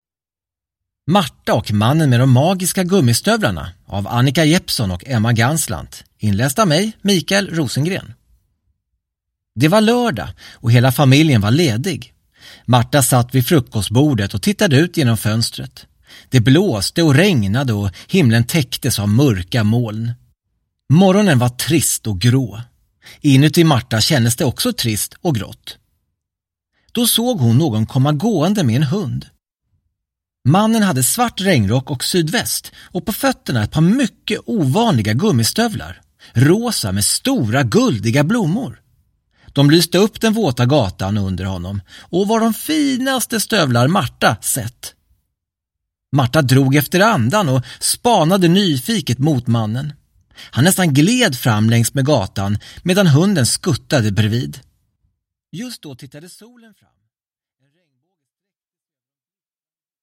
Marta och mannen med de magiska gummistövlarna (ljudbok) av Annika Jeppsson